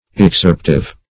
Search Result for " excerptive" : The Collaborative International Dictionary of English v.0.48: Excerptive \Ex*cerp"tive\, a. That excerpts, selects, or chooses.